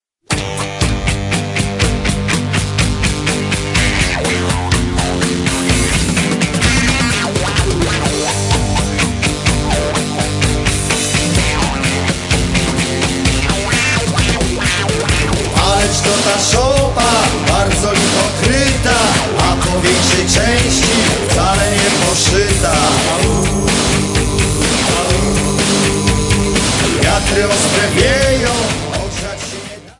Polish Christmas Carols